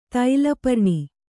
♪ taila parṇi